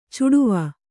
♪ cuḍuva